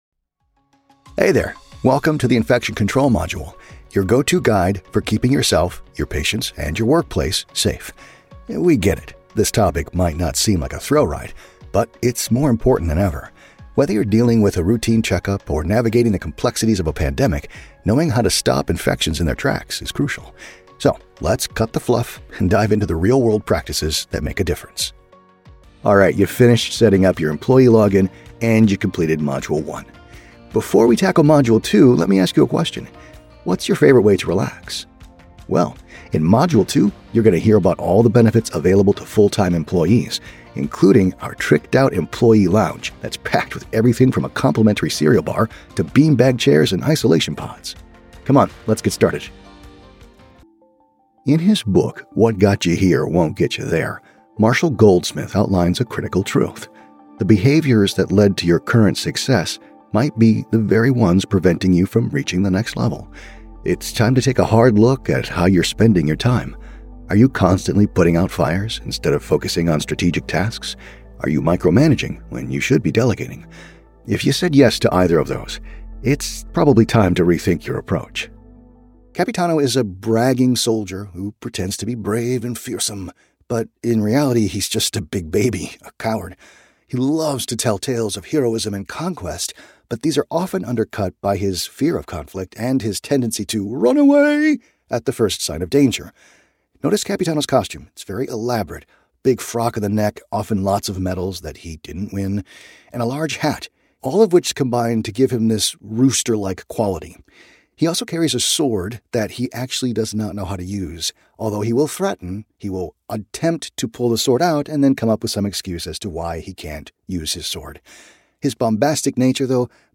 Easy-going, Guy-Next-Door, Conversational.
eLearning